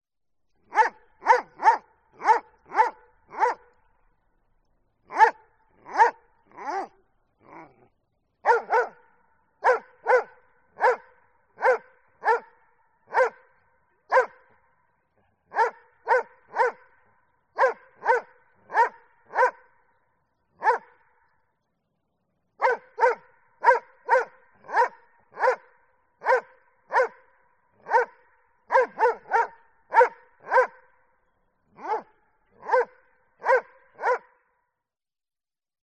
دانلود صدای پارس سگ از دور و فاصله زیاد از ساعد نیوز با لینک مستقیم و کیفیت بالا
جلوه های صوتی